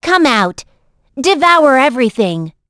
Gremory-Vox_Skill7.wav